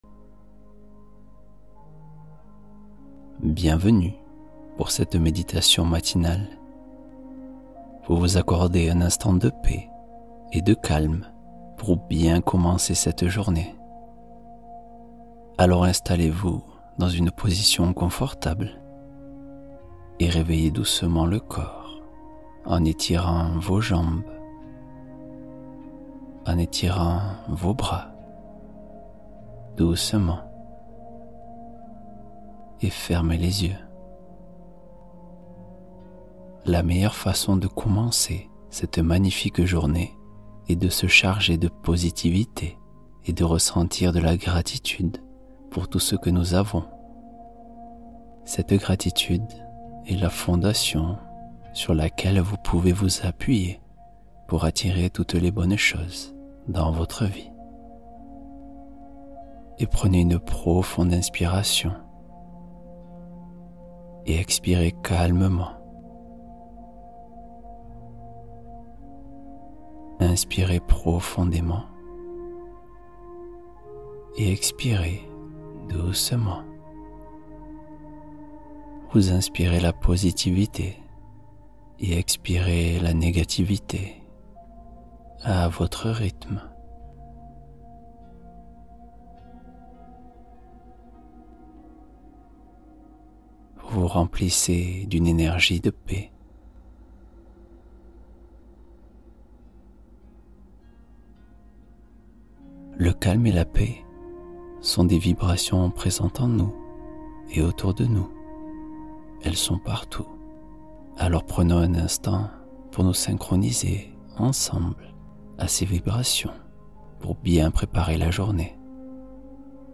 Soir apaisant : histoire guidée pour s’endormir en douceur